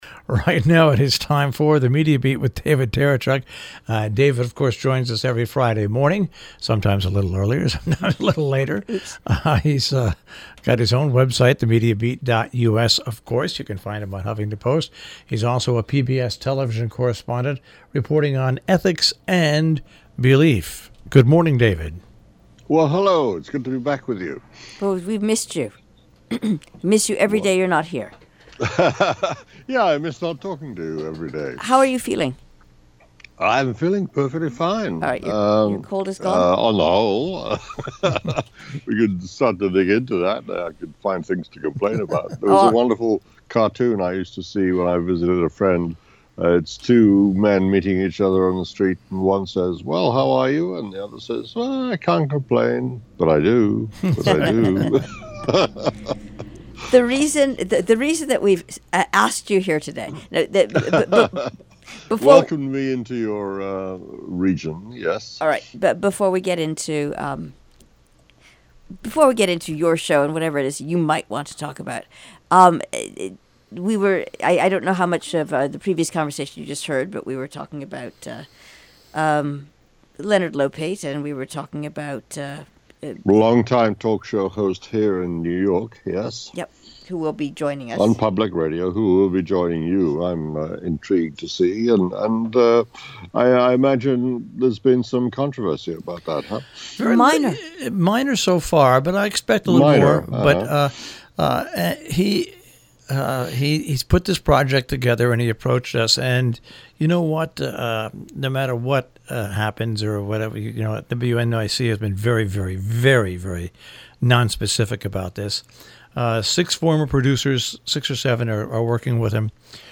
A radio version of THE MEDIA BEAT appears every week on the NPR Connecticut station WHDD – live on Friday morning and rebroadcast over the weekend.